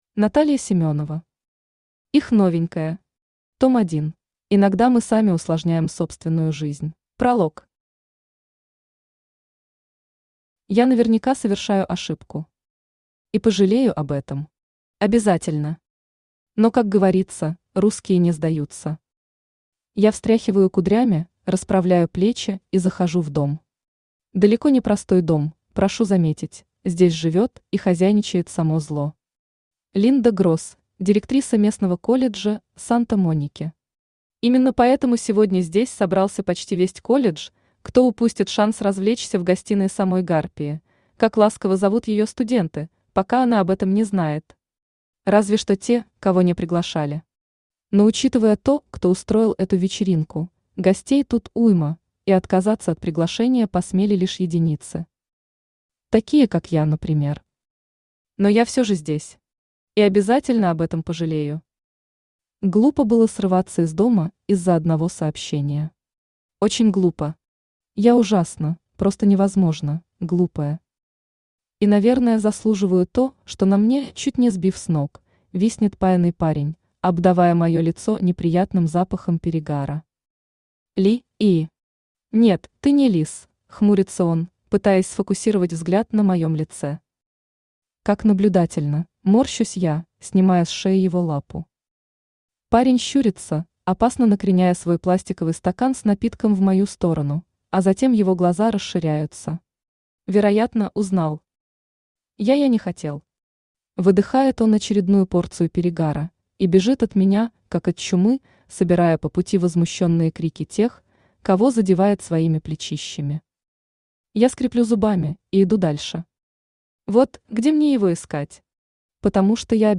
Аудиокнига Их новенькая. Том 1 | Библиотека аудиокниг
Том 1 Автор Наталья Семёнова Читает аудиокнигу Авточтец ЛитРес.